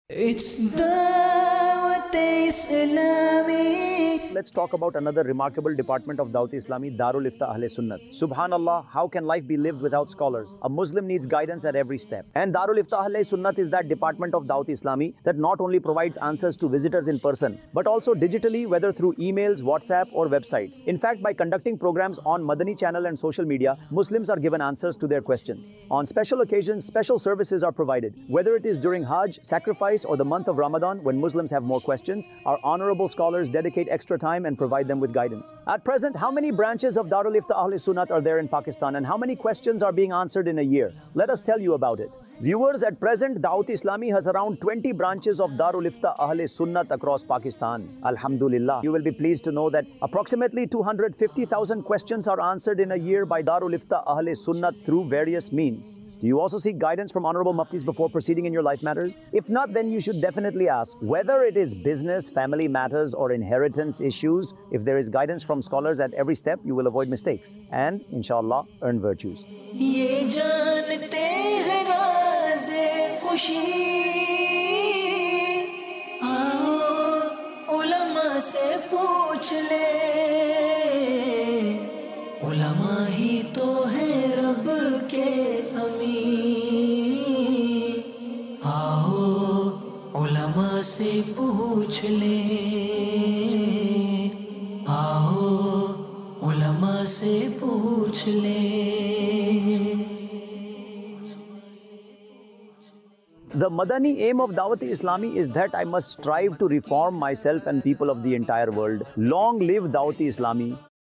Dar-ul-Ifta Ahlesunnat | Department of Dawateislami | Documentary 2025 | AI Generated Audio Mar 22, 2025 MP3 MP4 MP3 Share دارالافتاء اھلنّت | شعبہِ دعوت اسلامی | ڈاکیومینٹری 2025 | اے آئی جنریٹڈ آڈیو